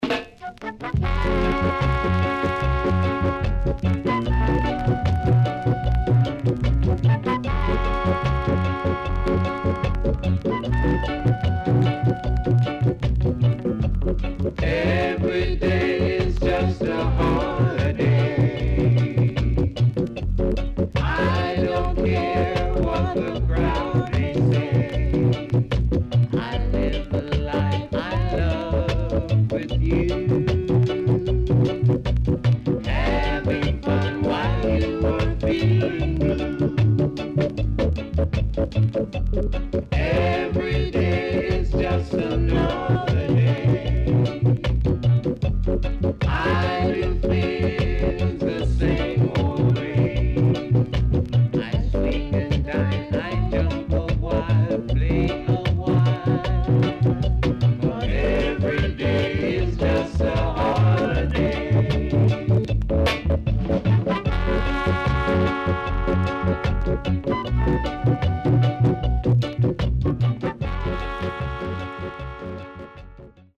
ROCKSTEADY
Organ Inst
SIDE B:所々チリノイズがあり、少しプチノイズ入ります。